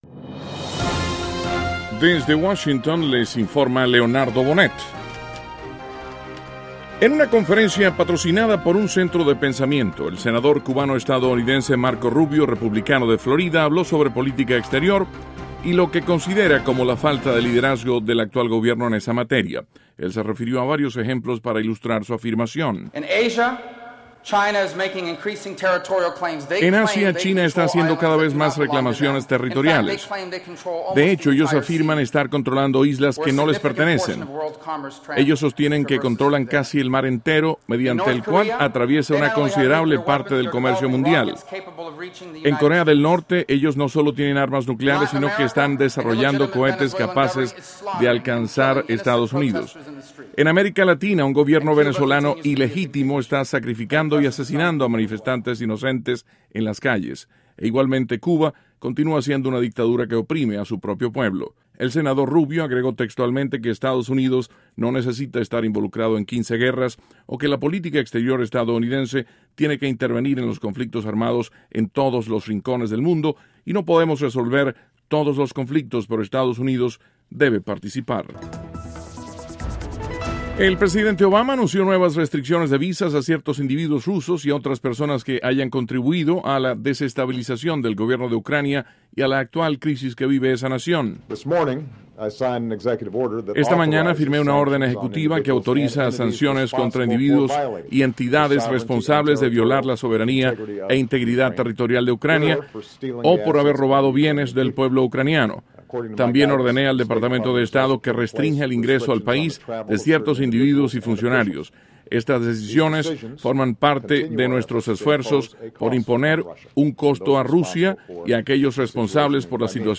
NOTICIAS - JUEVES, 6 DE MARZO, 2014
Duración: 3:12 Contenido: 1.- Estados Unidos anuncia impone algunas restricciones de visa a algunos ciudadanos rusos. (Sonido – Obama) 2.- Senador Marco Rubio habla de la falta de liderazgo en asuntos de política exterior. (Sonido – Rubio) 3.- Cantante Adam Lambert acompañará al grupo británico Queen en gira de verano por Estados Unidos.